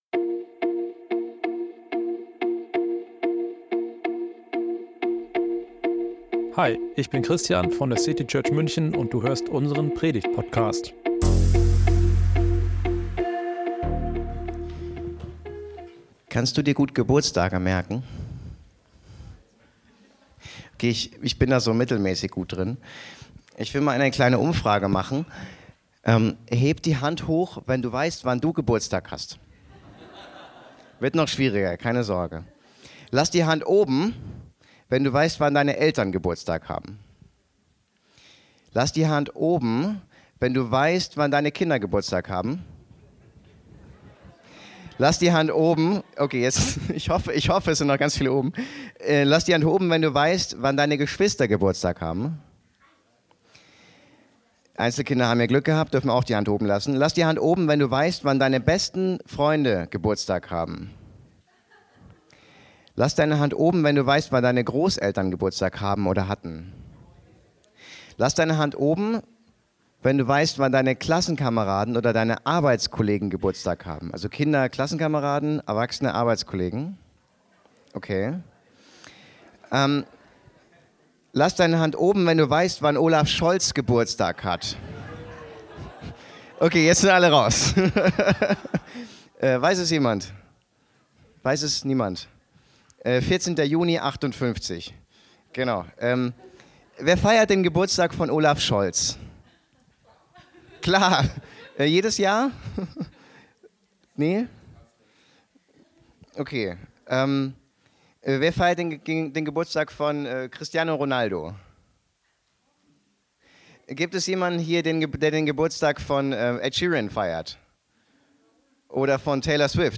Eine interaktive Geschichte.